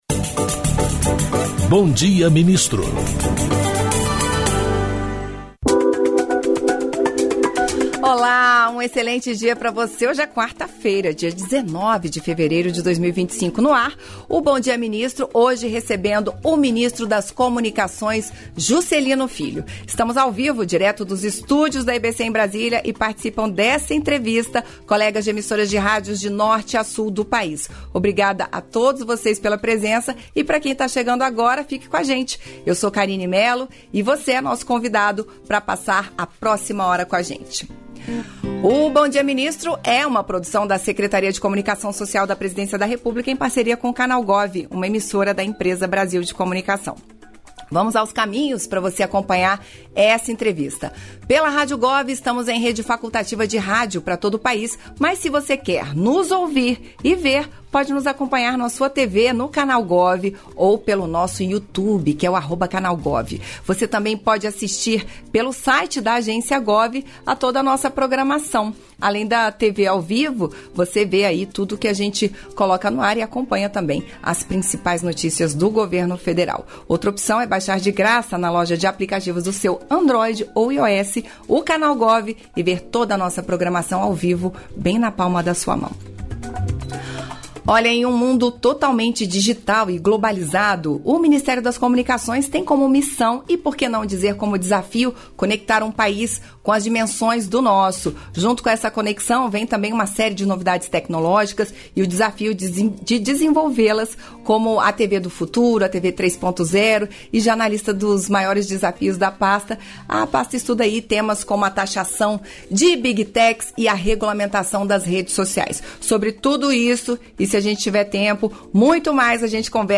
Íntegra da participação do ministro das Comunicações, Juscelino Filho, no programa “Bom Dia, Ministro” desta quarta-feira (19), nos estúdios da EBC.